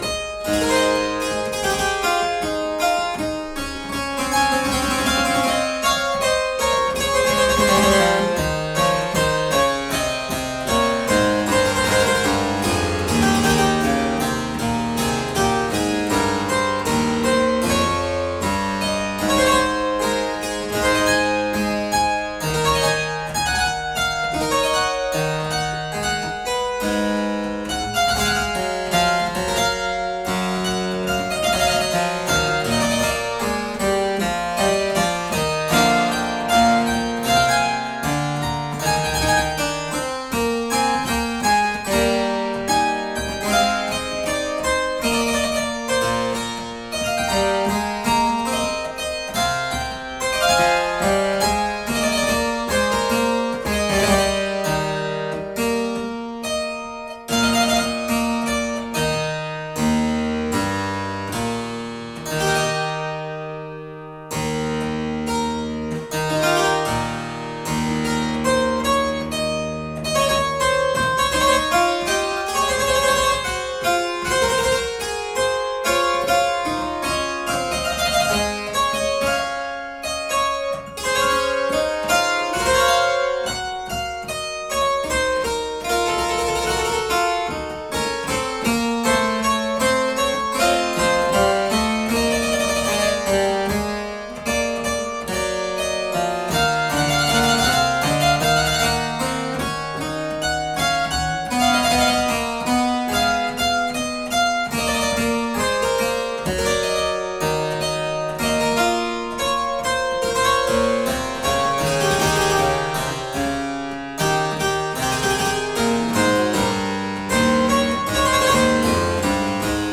I think it must the vividness with which he expresses the promise of hope, at the dawn of the 18th century, for his adopted (or soon-to-be adopted) country (so extraordinarily intuited by Bach, cf above), as well as his consummate ability (which recalls Chambonnières' own - perhaps Dieupart was indeed born after 1672?) to write with the maximum effectiveness in terms of tone-production for the harpsichord.
A major Allemande (played from the 1705 Walsh edition), which seems to start by evoking the trickle-down theory of wealth - "here people, all this can be yours..."